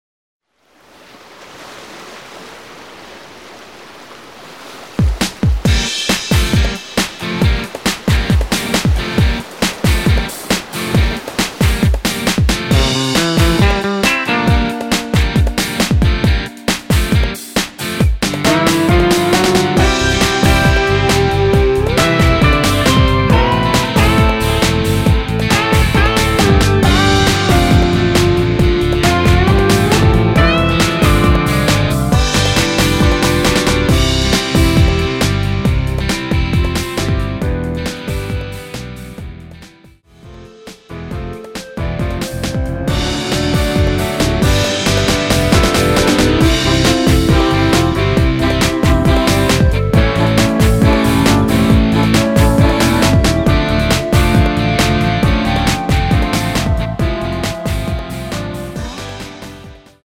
(-2)내린 멜로디 포함된 MR 입니다.(미리듣기 참조)
앞부분30초, 뒷부분30초씩 편집해서 올려 드리고 있습니다.
중간에 음이 끈어지고 다시 나오는 이유는
(멜로디 MR)은 가이드 멜로디가 포함된 MR 입니다.